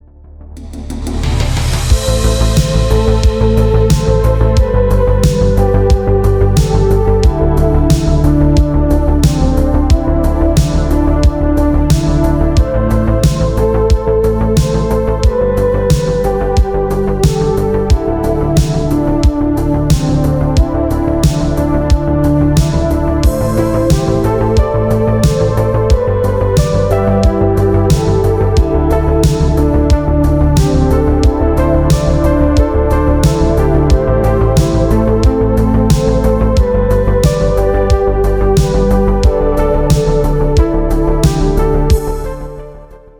• Качество: 320, Stereo
атмосферные
Electronic
спокойные
без слов
dark ambient